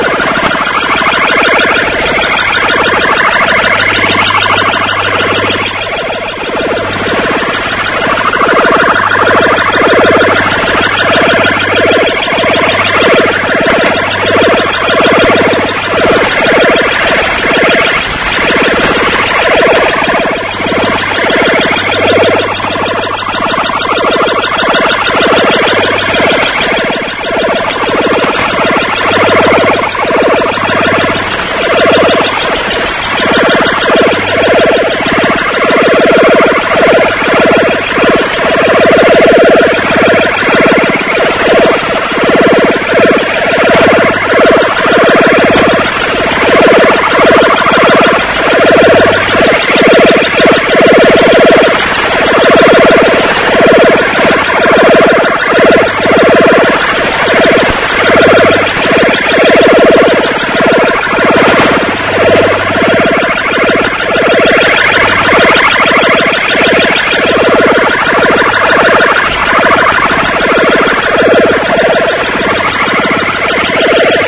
Мир коротких волн. DX и SWL.
0338 UTC, 4840 кГц.
Глушение